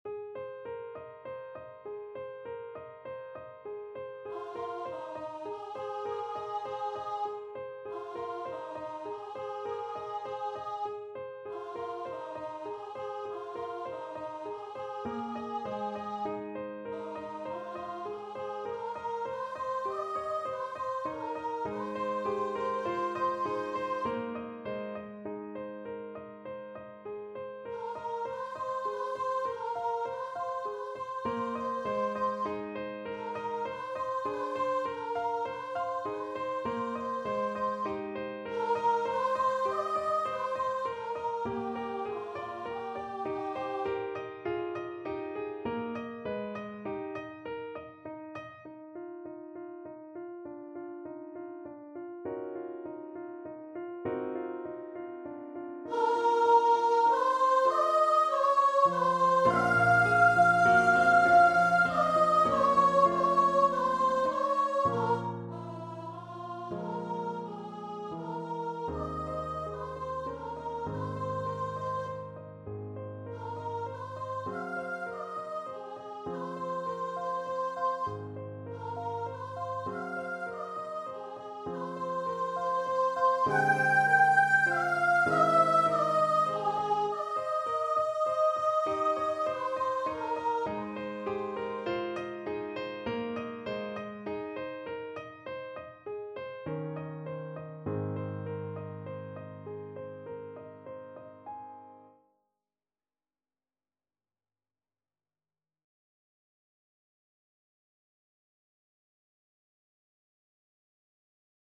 Voice version
~ = 120 Allegretto
9/4 (View more 9/4 Music)
Classical (View more Classical Voice Music)